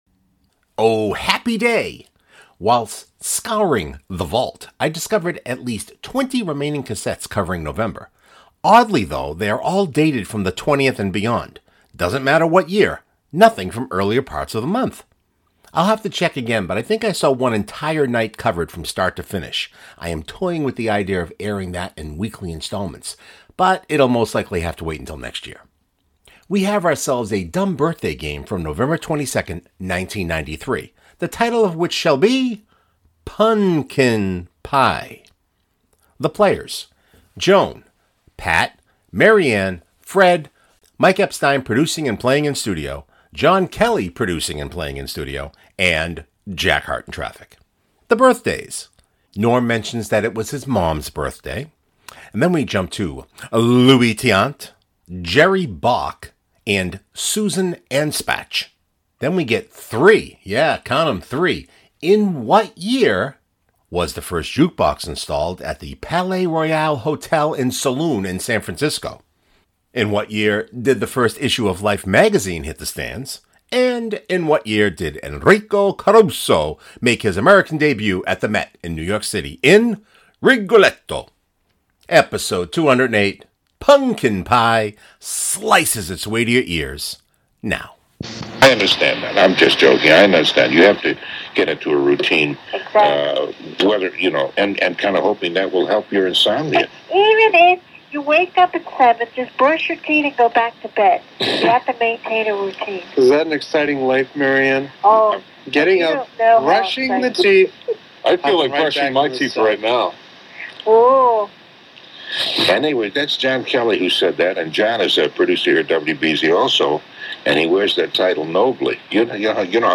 Whilst scouring the Vault I discovered at least 20 remaining cassettes covering November.